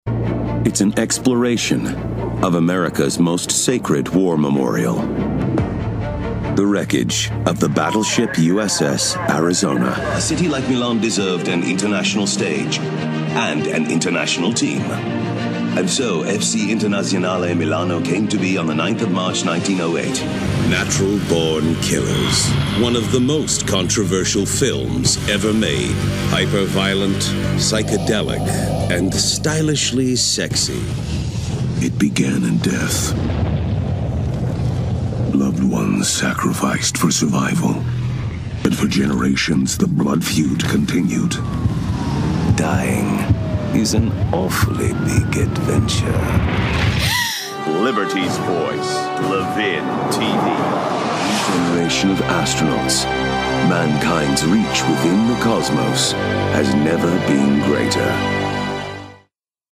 Friendly, Warm, Conversational.
Narration